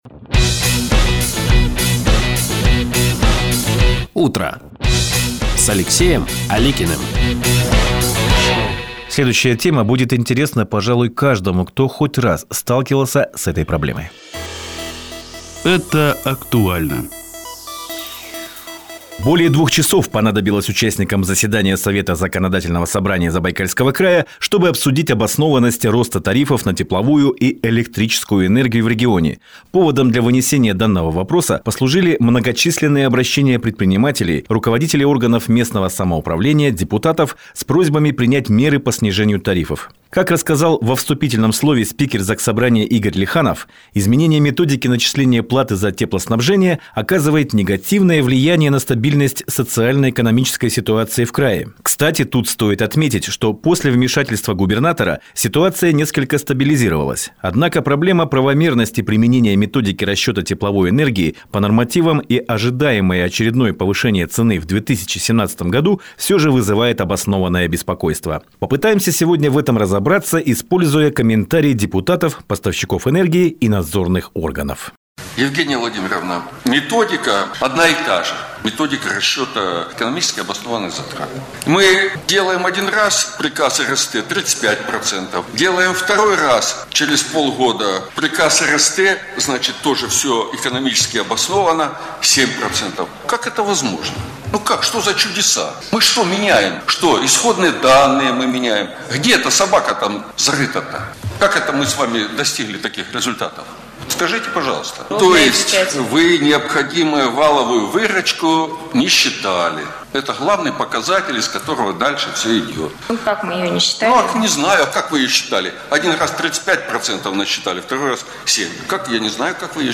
Из зала заседания без купюр: Совет парламента выяснял обоснованность тарифов – материал «Радио России-Чита»